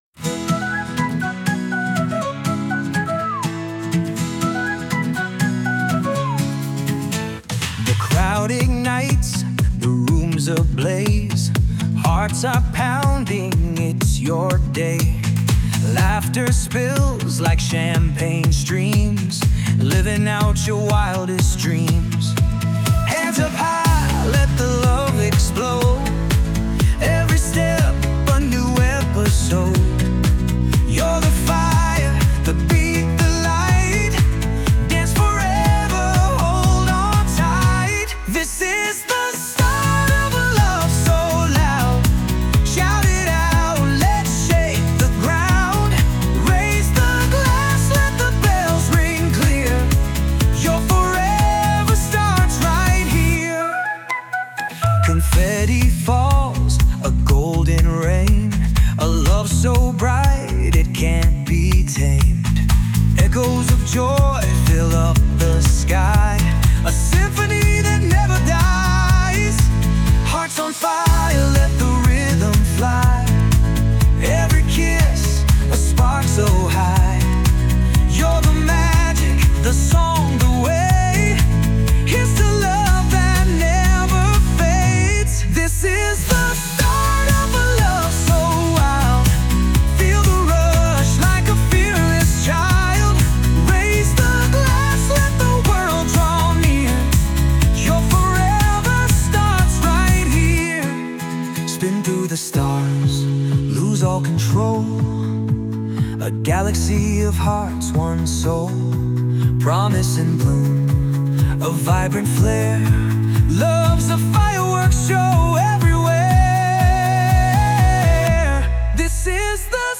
洋楽男性ボーカル著作権フリーBGM ボーカル
著作権フリーオリジナルBGMです。
男性ボーカル（洋楽・英語）曲です。
今回はオープニングムービーで使えそうな明るい曲を目指しました✨